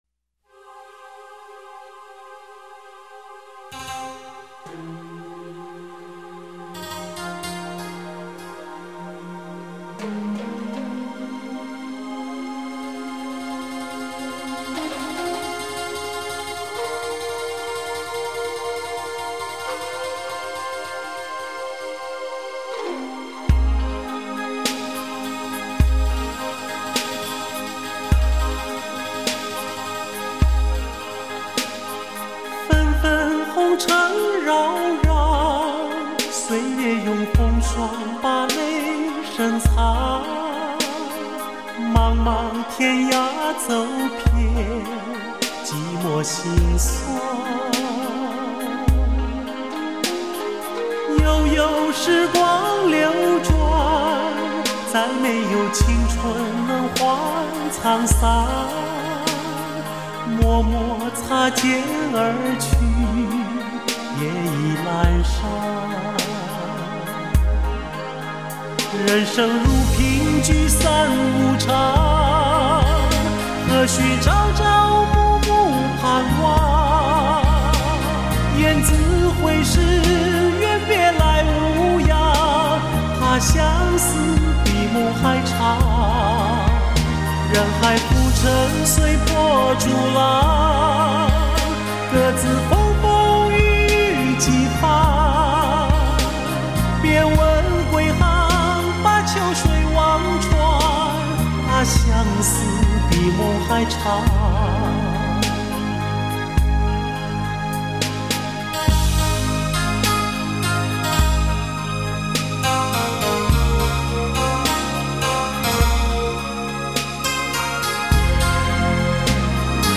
依然这么深情款款，音域游走，特别适合想念